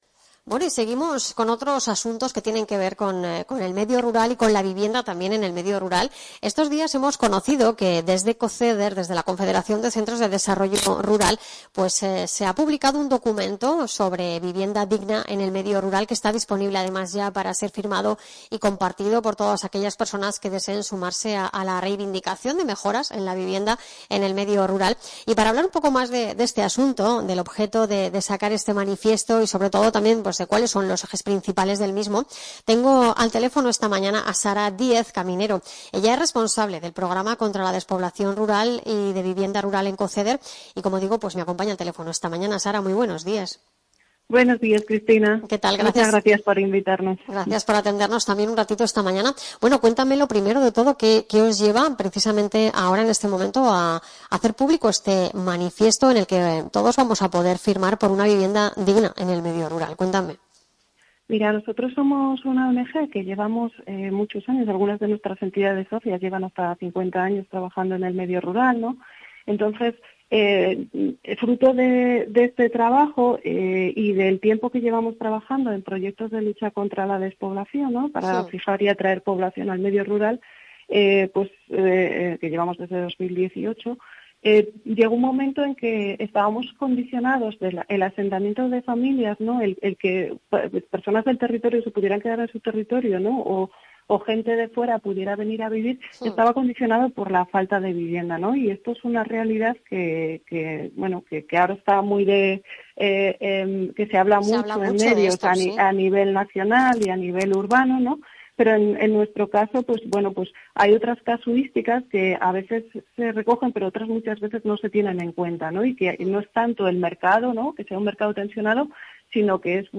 Entrevista COCEDER La Asociación Cultural Grío, con sede en Codos y entidad asociada a COCEDER, lleva años trabajando en esta misma línea.